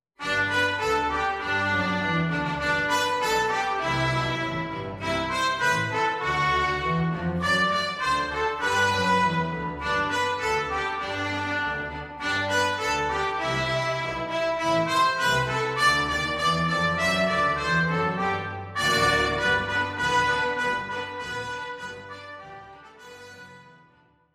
Flexible Ensemble (Woodwind, Brass and String instruments)
Woodwind, Strings and Trumpet